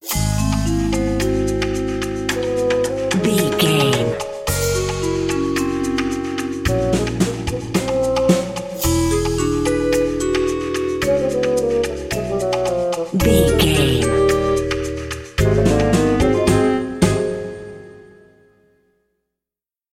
Music
Aeolian/Minor
C#
percussion
flute
bass guitar
silly
circus
goofy
comical
cheerful
perky
Light hearted
sneaking around
quirky